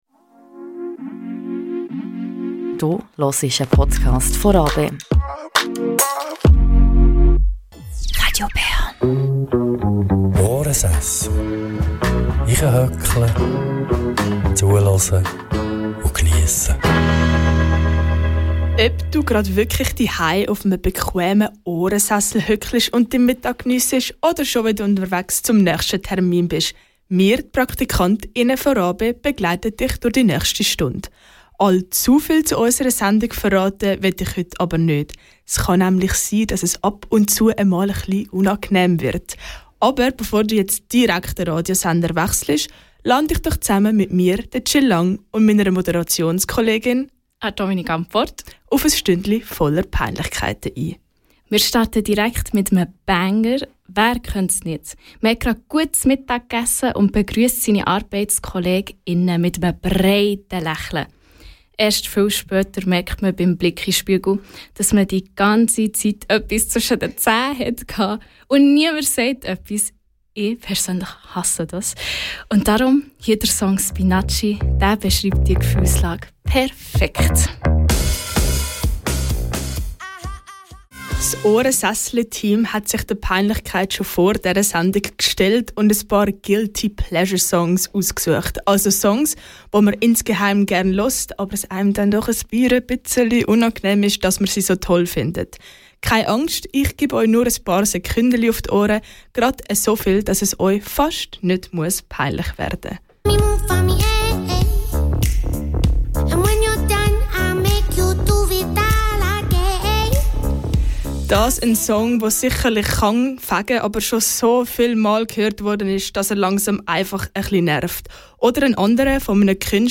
In einer Strassenumfrage gehen wir der Frage nach: Was ist dir peinlich und was überhaupt nicht?
In diesen Episoden hörst du den Zusammenschnitt der live-Sendung.